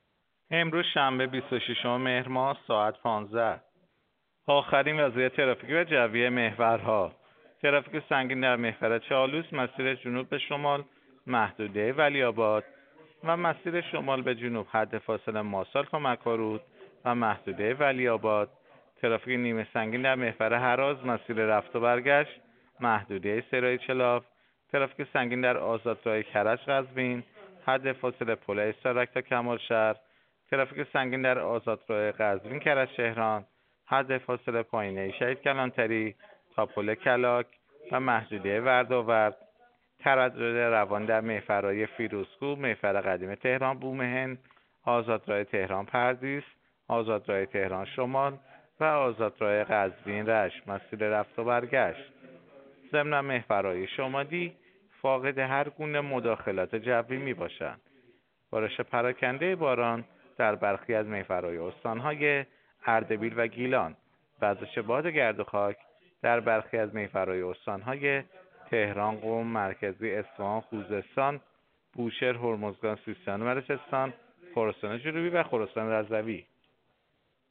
گزارش رادیو اینترنتی از آخرین وضعیت ترافیکی جاده‌ها ساعت ۱۵ بیست‌وششم مهر؛